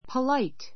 polite A2 pəláit ポ ら イ ト 形容詞 丁寧 ていねい な, 礼儀 れいぎ 正しい, 礼儀をわきまえている ⦣ 友達同士のようにざっくばらんではないこと. a polite answer a polite answer 丁寧な返事 She is polite to everybody.